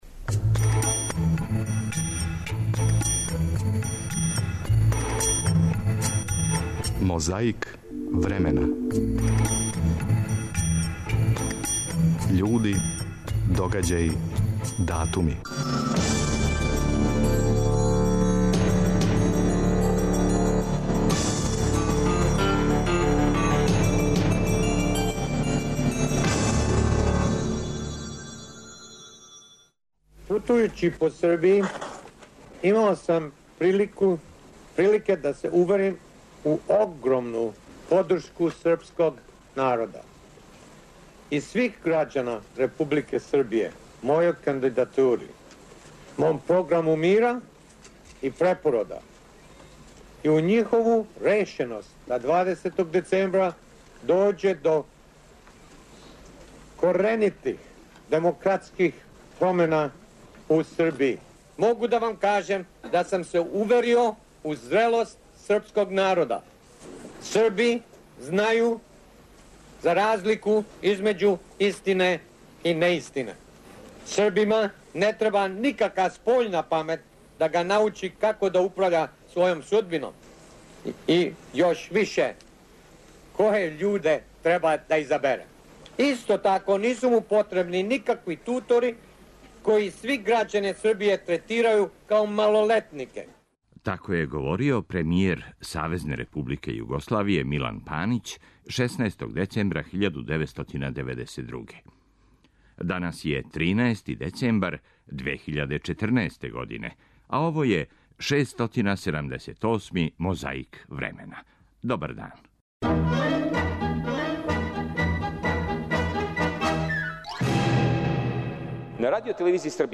Уз френетичан аплауз и скандирање, друг Тито се обратио присутнима на заједничком конгресу СКОЈ-а и Народне омладине.
Подсећа на прошлост (културну, историјску, политичку, спортску и сваку другу) уз помоћ материјала из Тонског архива, Документације и библиотеке Радио Београда.